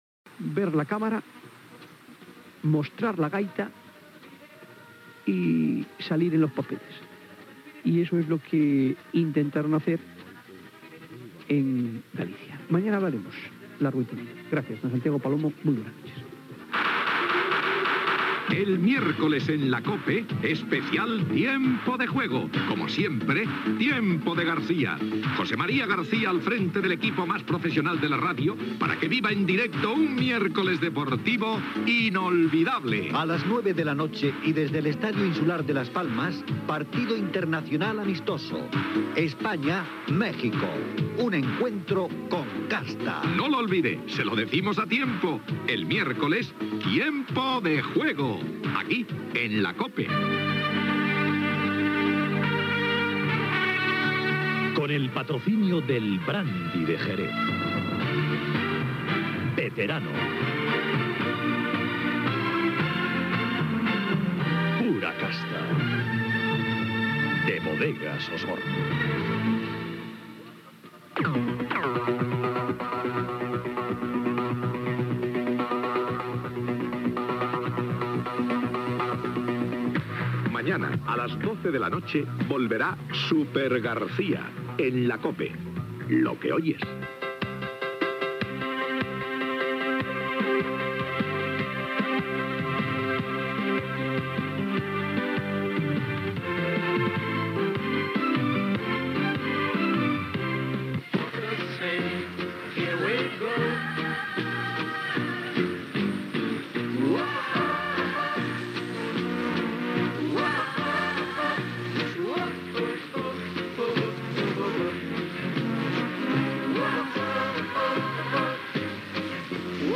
Anunci de "Tiempo de juego", publicitat, careta de sortida del programa, indicatiu de la cadena, música. Moment en que COPE Miramar tanca la freqüencia dels 666 KHz i s'escolta una emissora alemanya